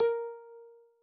message-new-instant.wav